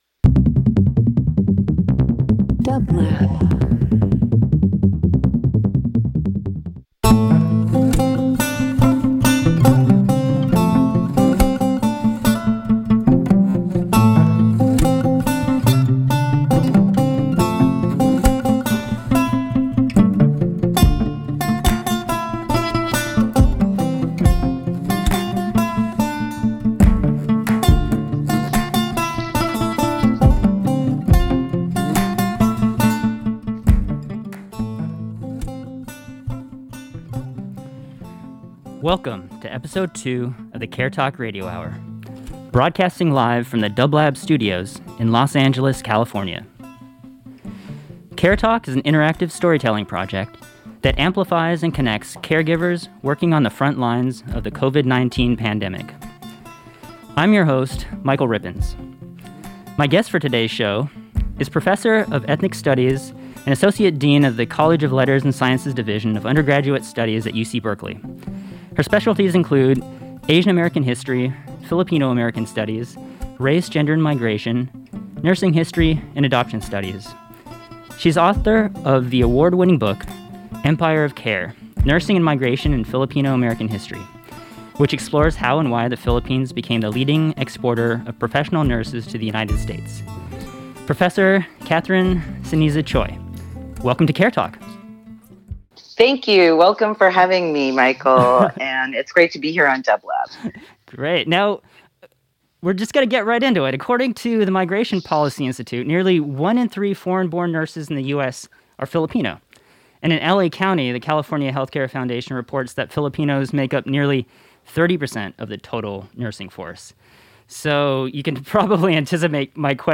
Interview
Talk Show Broadcasting live and in-person from the dublab studios